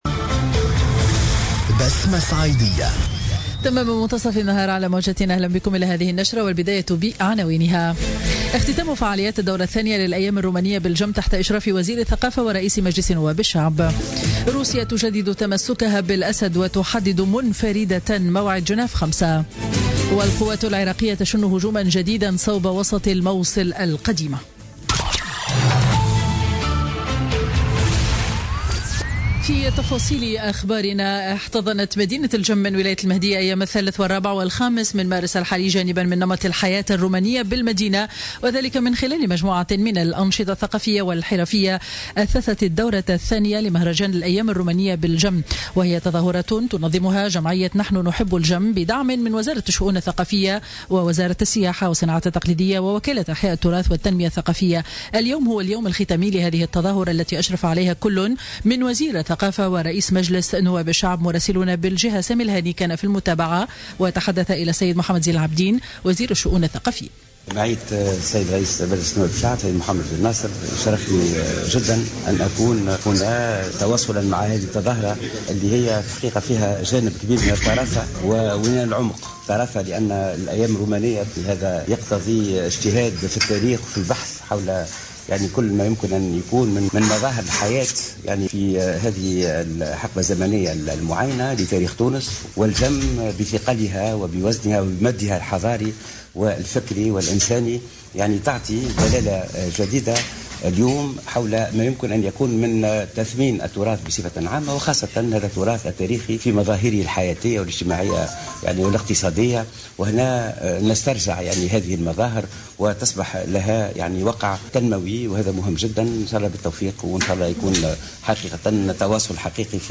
نشرة أخبار منتصف النهار ليوم الأحد 5 مارس 2017